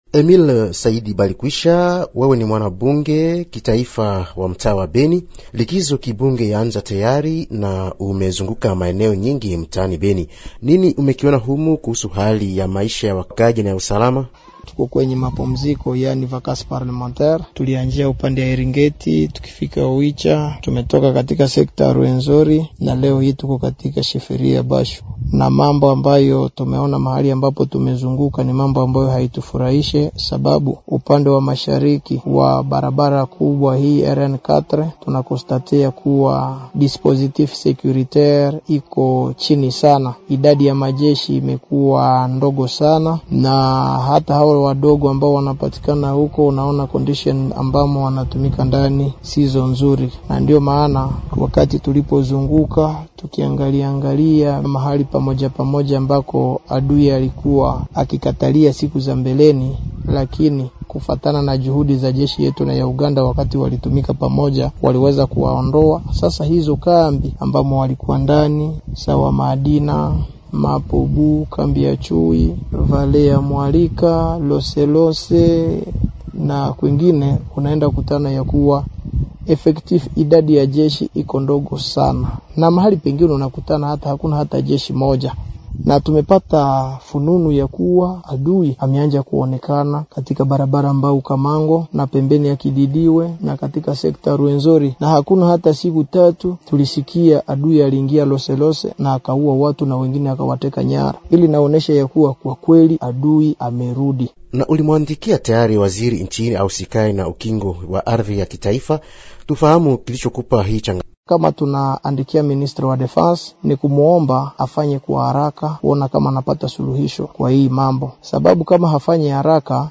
Katika taarifa yake ya tarehe saba Januari, 2025,  iliyoelekezwa kwa Waziri wa Ulinzi, mchaguliwa wa tarafa la Beni anazungumzia juu ya upungufu wa usalama ambao unahitaji uingiliaji kati wa haraka ili kuzuia hali mbaya zaidi. Mbunge Saidi Balikwisha Emile akiongea kwa simu